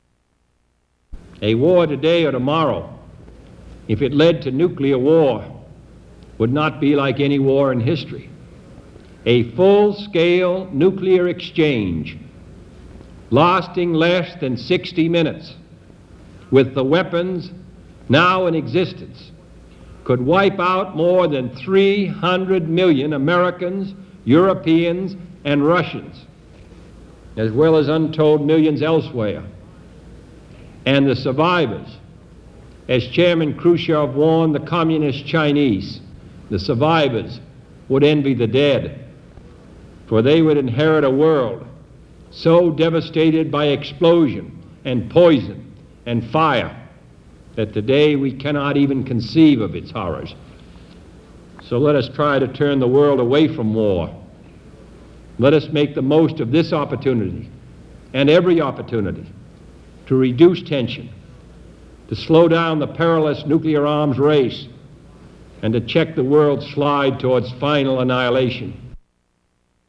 Excerpt from U.S. President John F. Kennedy's speech on the Test Ban Treaty
Excerpt from a speech by U.S. President John F. Kennedy on the Test Ban Treaty describing the horrors of nuclear warfare.